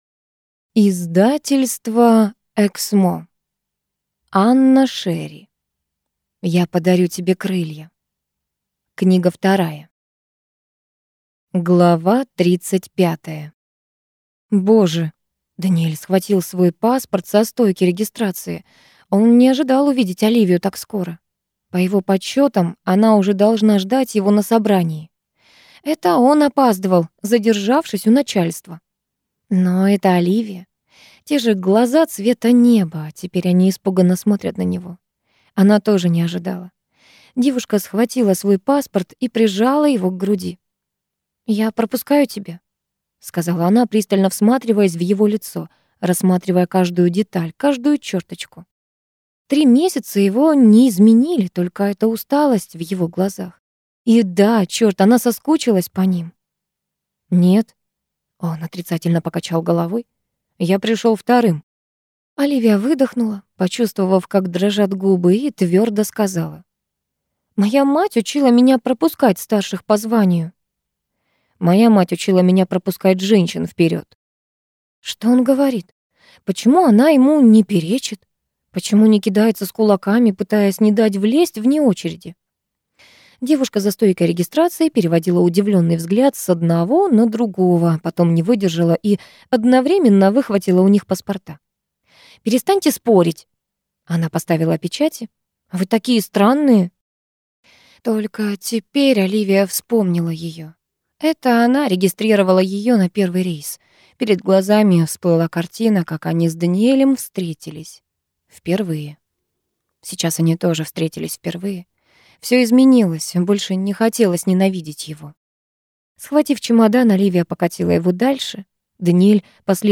Аудиокнига Я подарю тебе крылья. Книга 2 | Библиотека аудиокниг